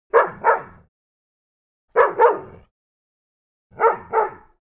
دانلود آهنگ سگ برای زنگ موبایل از افکت صوتی انسان و موجودات زنده
دانلود صدای سگ برای زنگ موبایل از ساعد نیوز با لینک مستقیم و کیفیت بالا
جلوه های صوتی